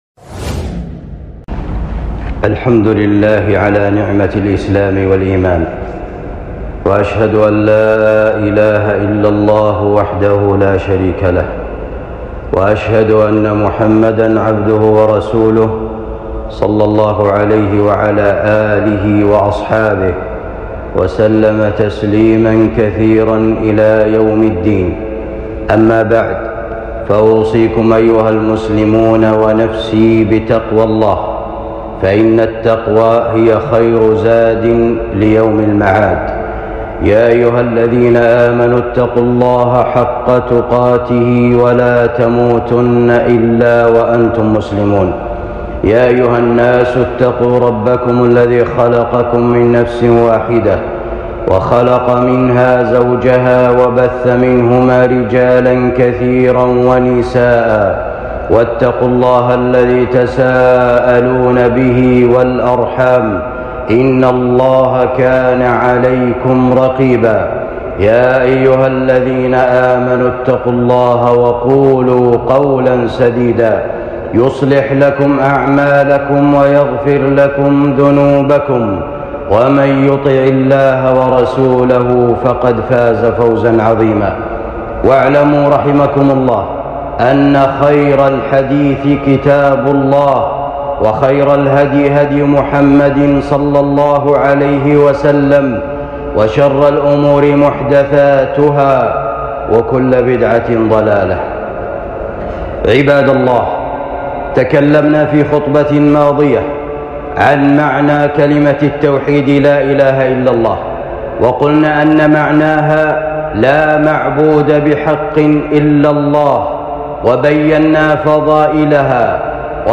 خطبة جمعة بعنوان شروط (لا إله إلا الله) ونواقضها